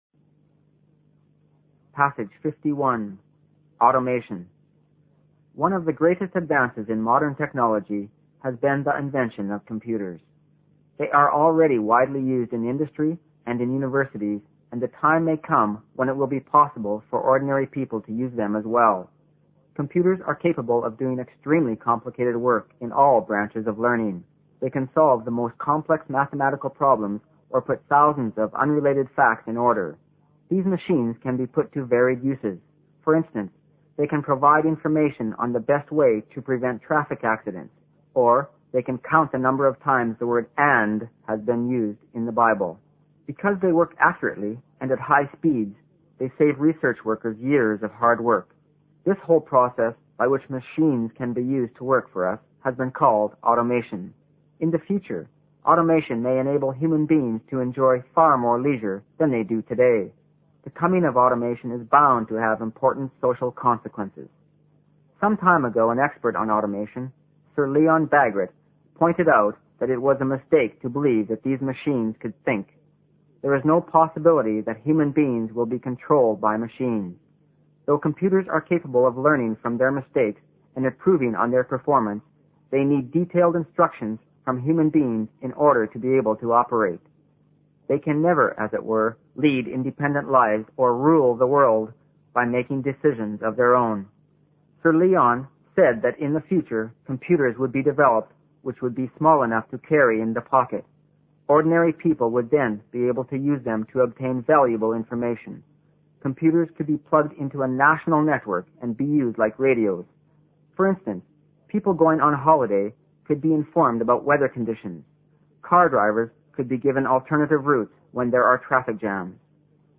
新概念英语85年上外美音版第三册 第51课 听力文件下载—在线英语听力室